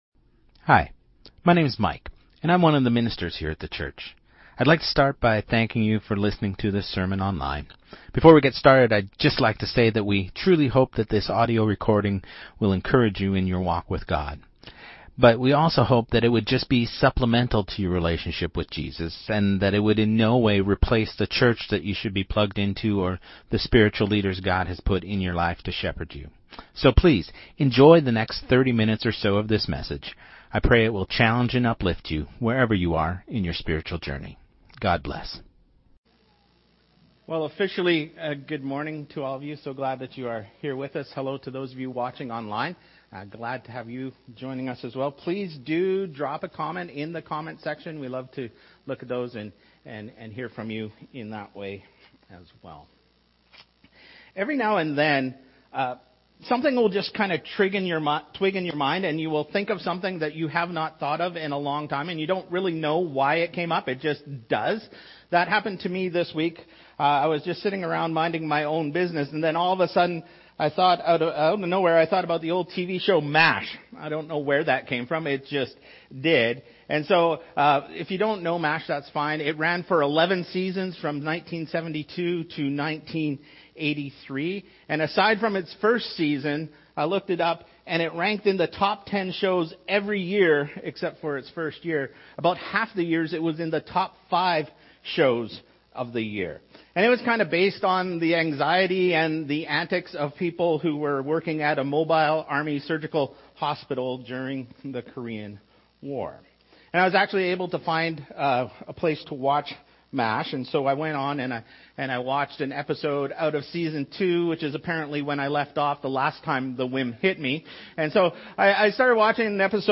Sermon2025-12-07